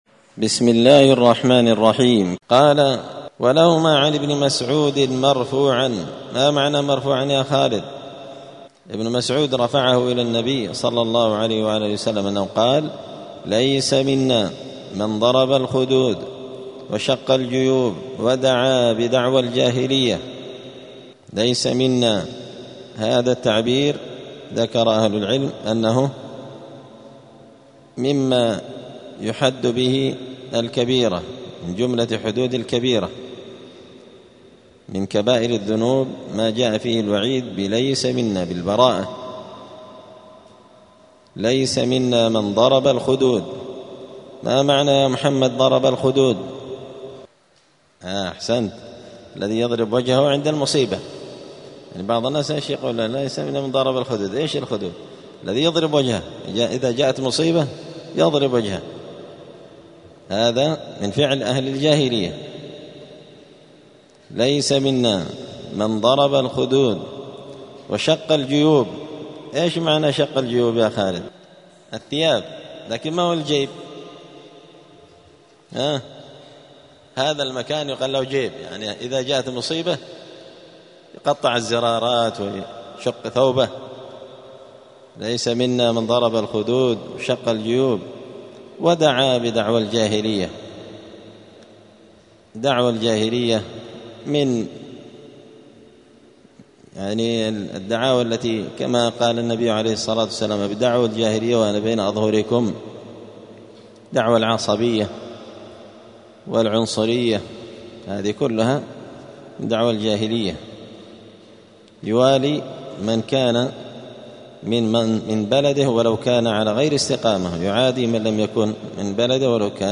دار الحديث السلفية بمسجد الفرقان قشن المهرة اليمن
*الدرس الثامن والتسعون (98) {تابع لباب من الإيمان بالله الصبر على أقدار الله}*